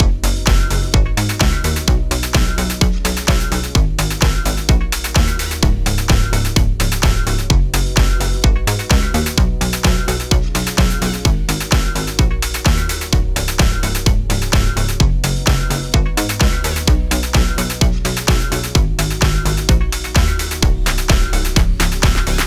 Gb Major
Poppin Low
Classic Warm
Game Voice
Slow Feeling
Music - Kitchen Sounds